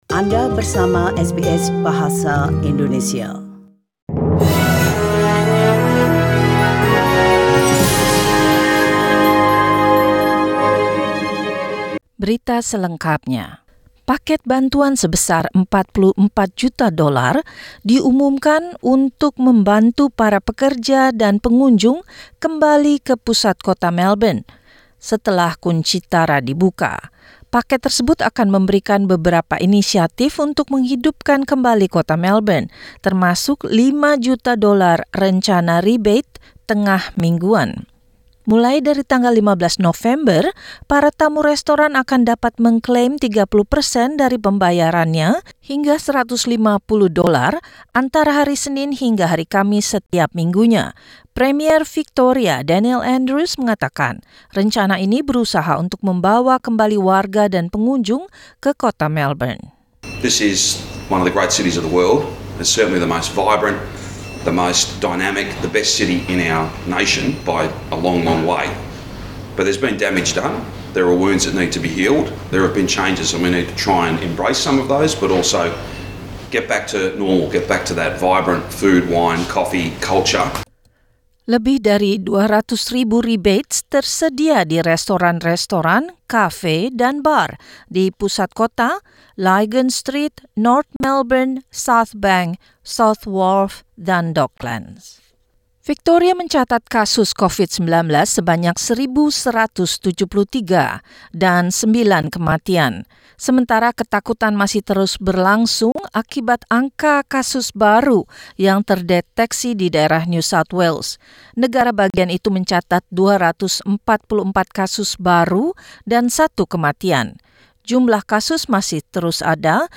SBS Radio News in Indonesian - Sunday, 7 November 2021
Warta Berita Radio SBS dalam Bahasa Indonesia Source: SBS